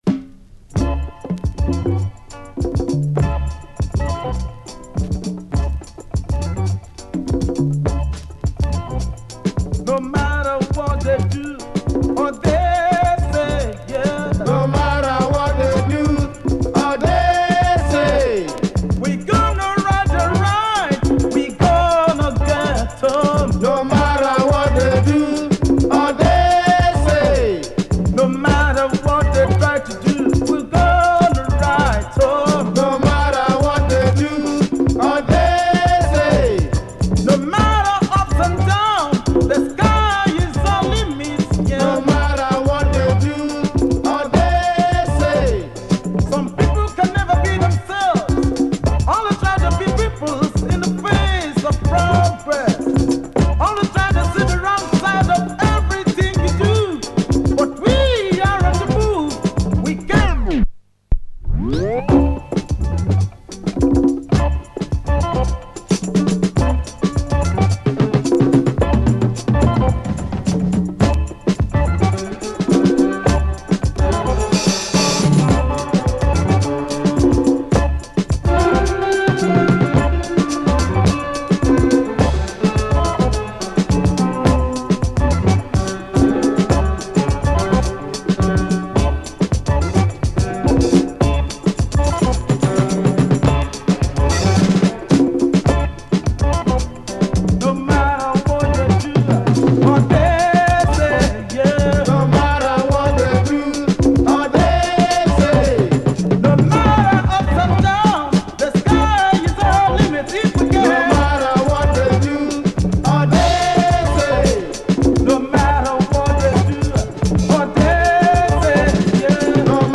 Nigerian afro rock outfit.
has a great organ breakdown.